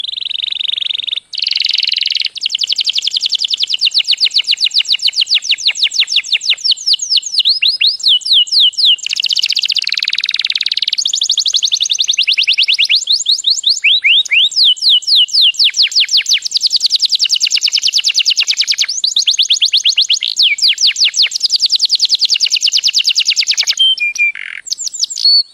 ringtone pajarito 6